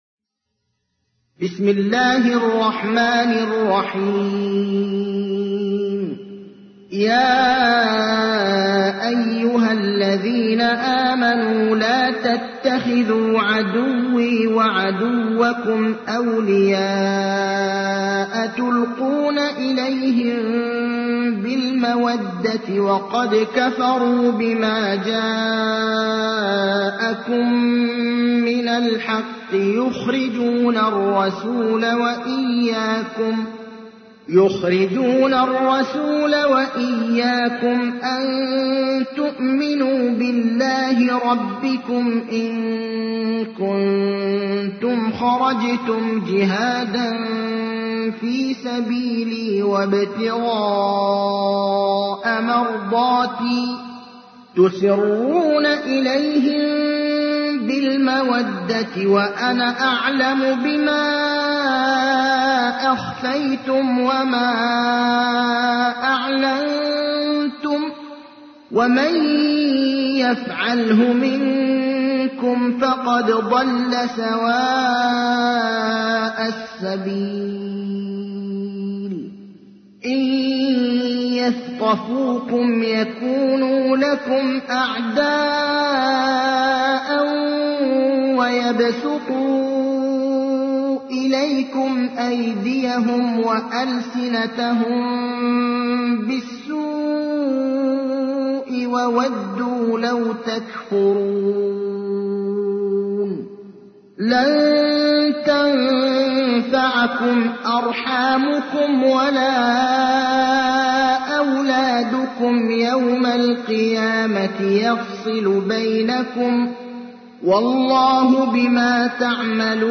تحميل : 60. سورة الممتحنة / القارئ ابراهيم الأخضر / القرآن الكريم / موقع يا حسين